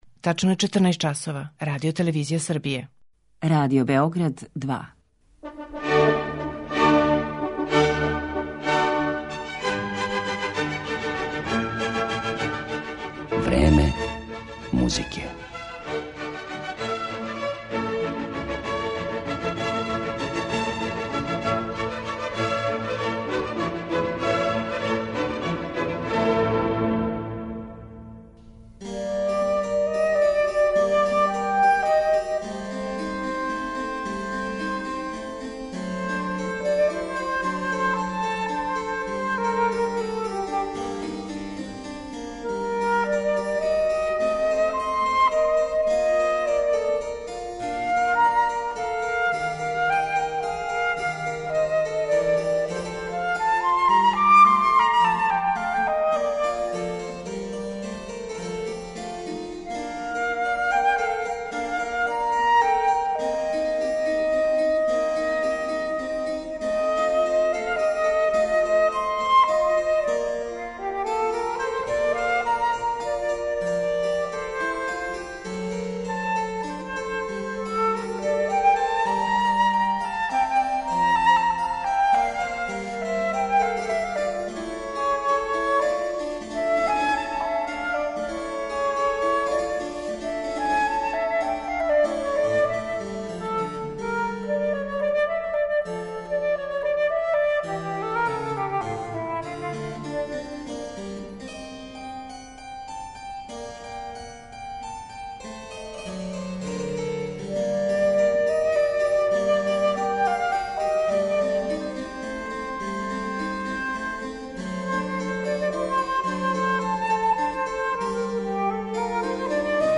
Израелска флаутисткиња Шарон Бецали
У данашњем Времену музике чућемо је у делима Јохана Себастијана Баха, Франсиса Пуленка, Карла Рајнекеа, Хосеа Сербиера, Геа Ган-руа, Волфганга Амадеуса Моцарта и Георга Фридриха Хендла.